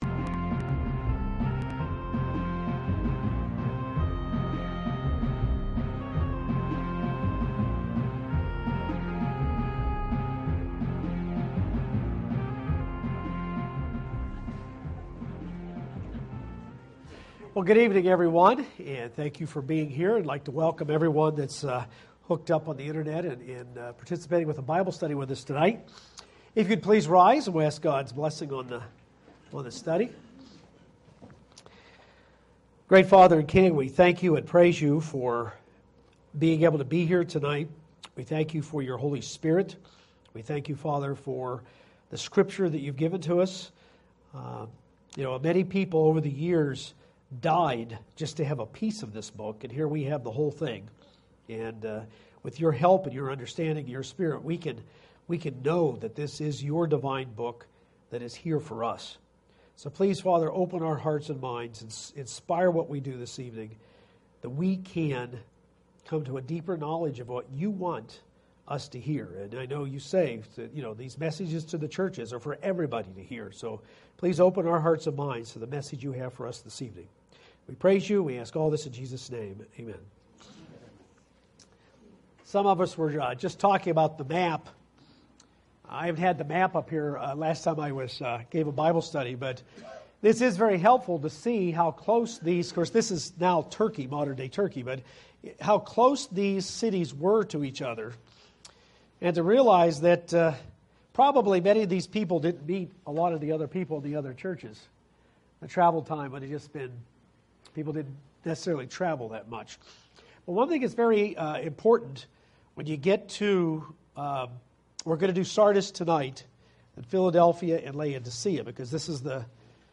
This is the sixth part in the Bible study series: The Churches of Revelation.